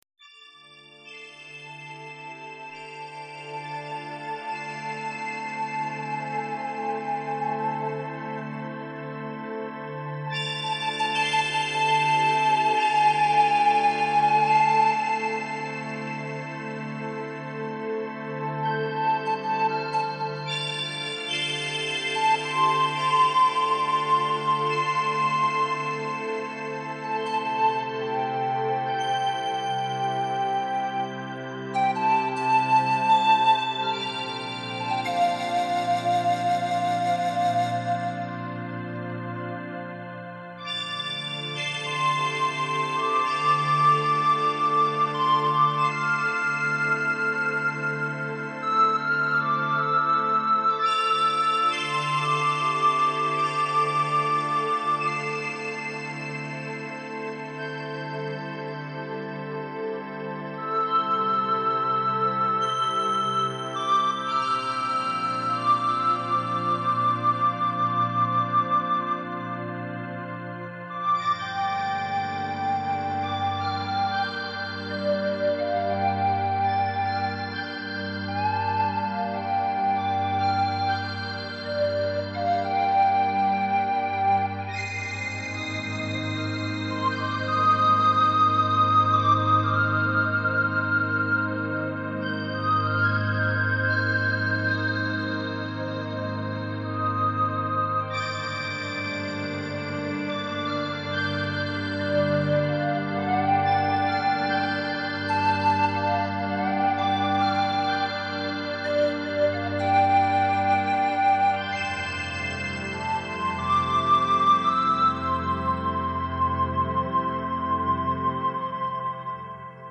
Music for relaxation and reflection
keyboard player